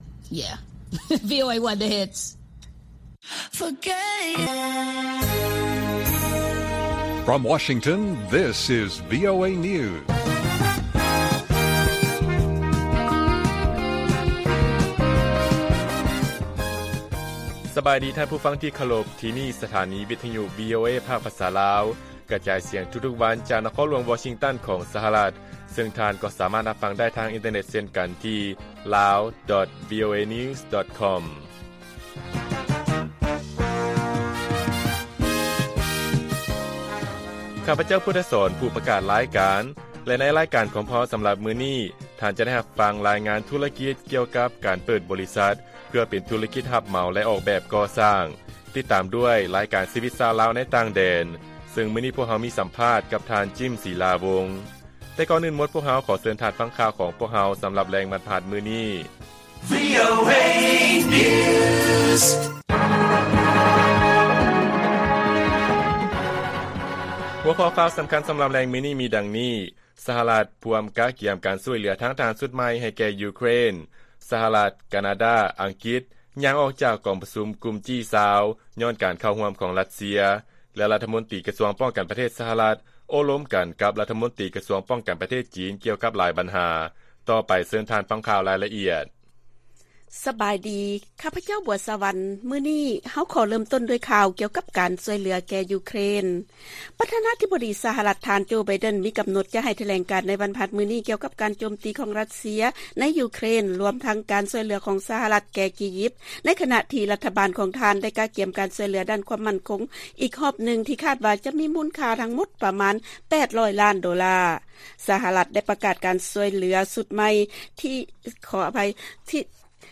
ລາຍການກະຈາຍສຽງຂອງວີໂອເອ ລາວ: ສະຫະລັດ ພວມກະກຽມການຊ່ວຍເຫລືອທາງທະຫານຊຸດໃໝ່ໃຫ້ແກ່ຢູເຄຣນ